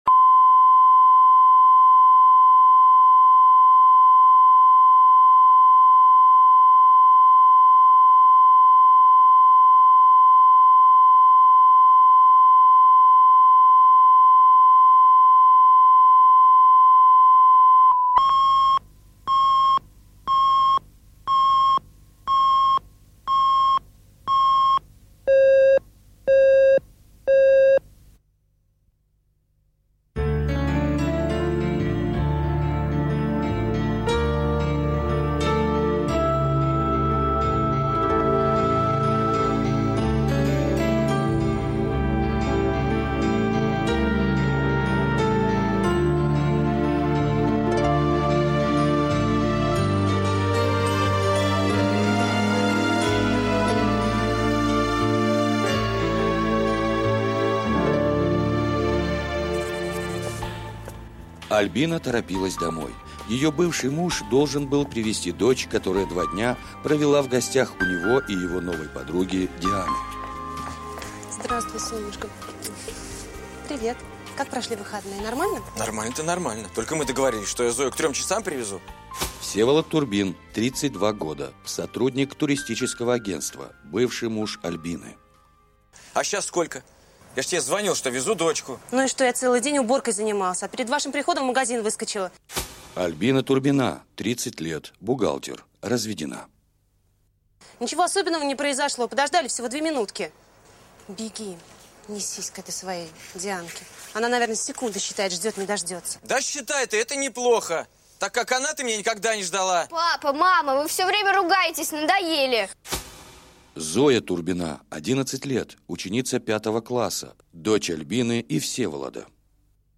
Аудиокнига Секретики по выходным